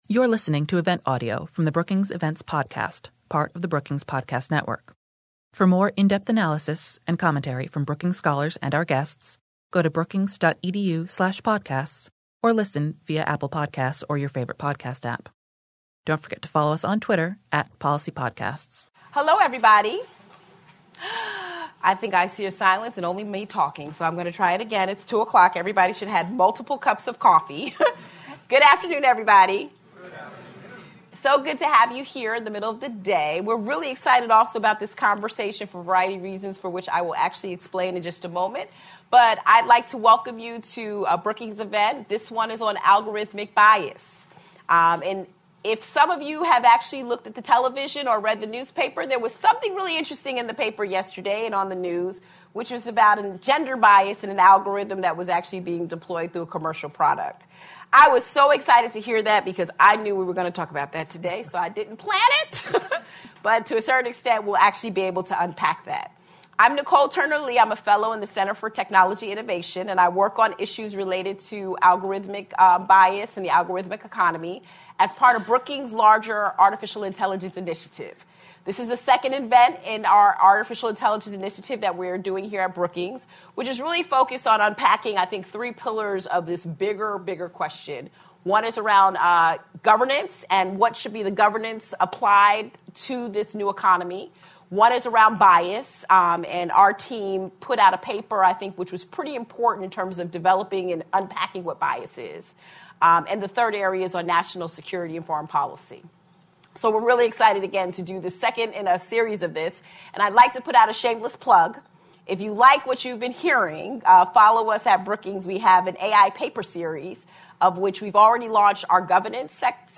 On November 12, Brookings hosted a panel of experts in a conversation that unpacks how biases within machine learning algorithms play out differently for online users.
After the session, panelists answered questions from the audience.